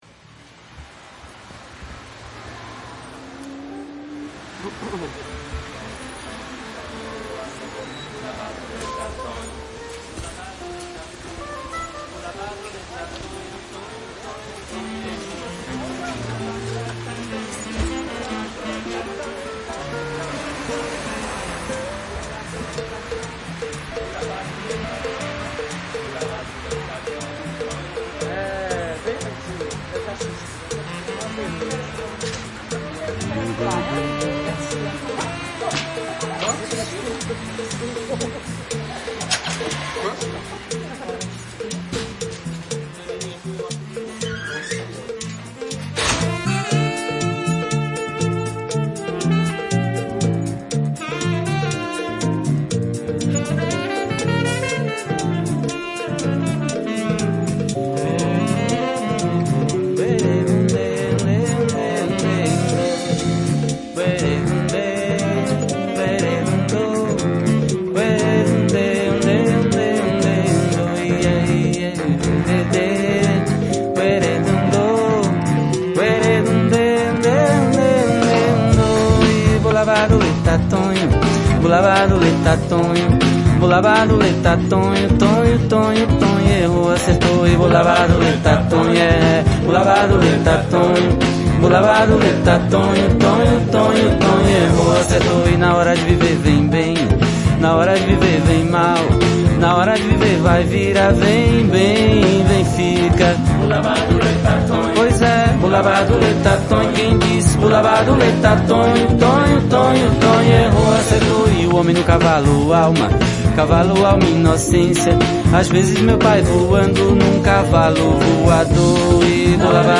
Genre : MPB